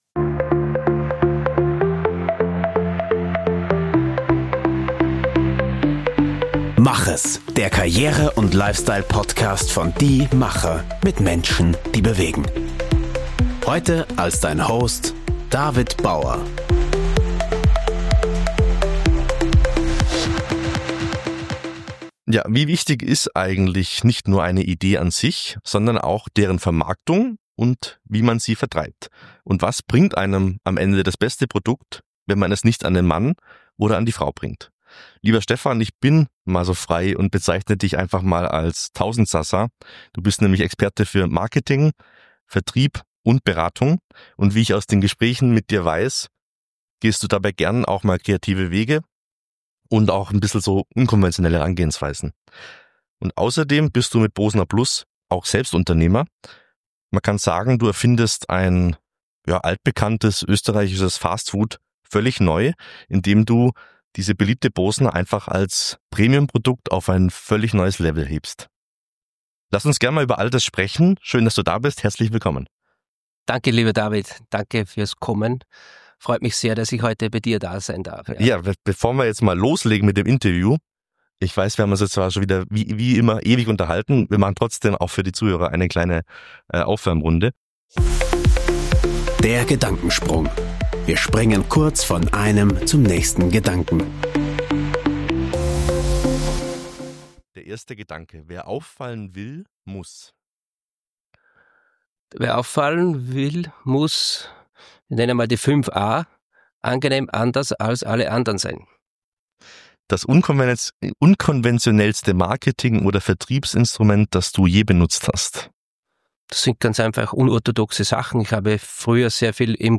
Im Interview erfahren wir